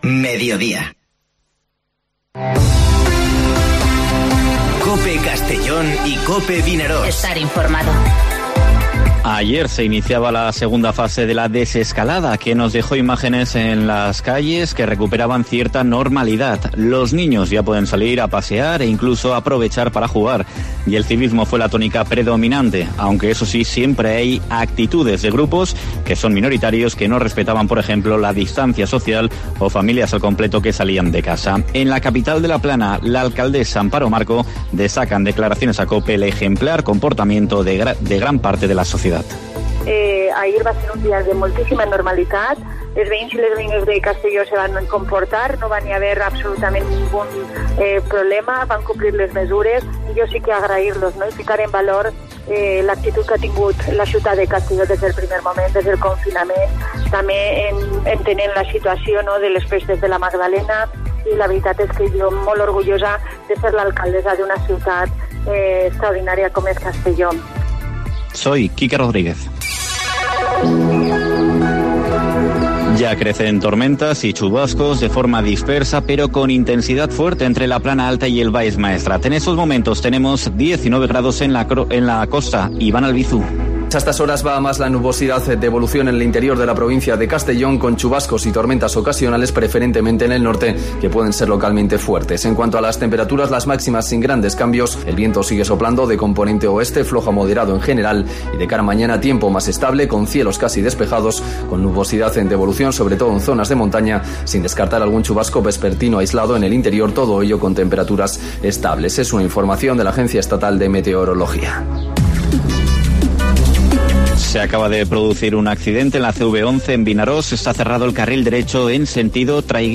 Informativo Mediodía COPE en la provincia de Castellón (27/04/2020)